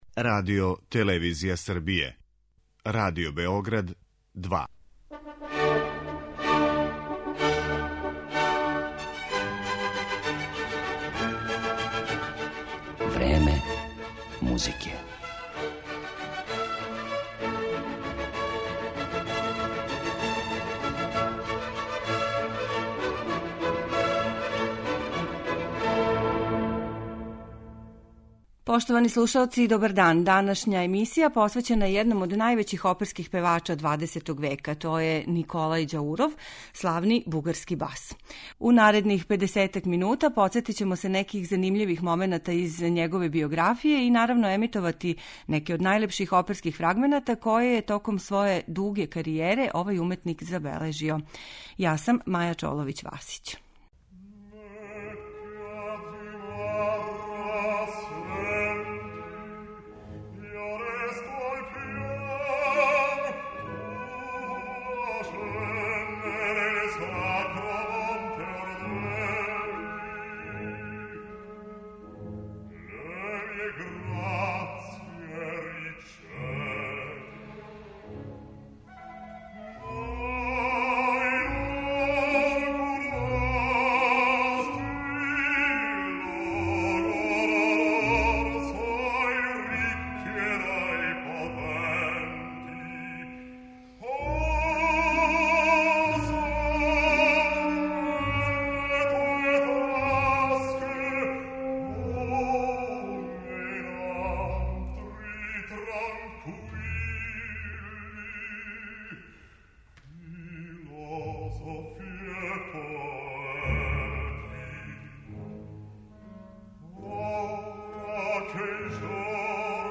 оперски ликови